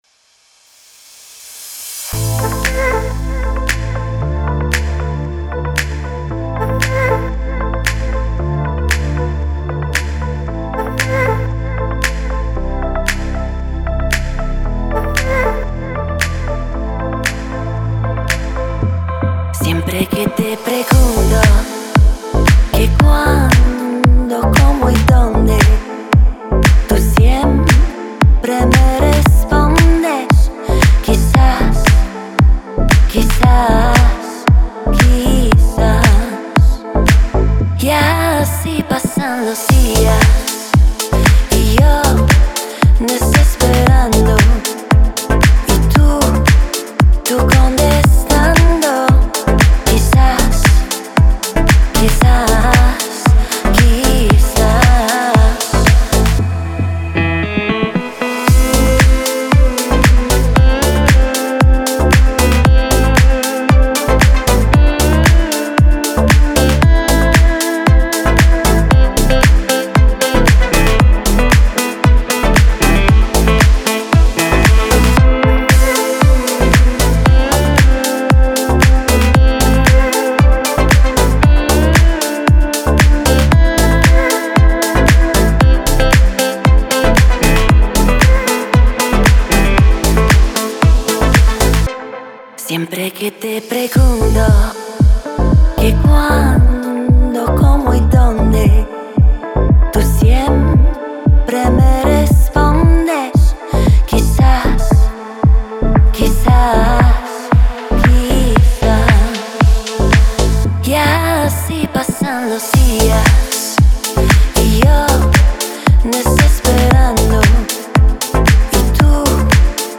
Энергичная зарубежка
энергичная музыка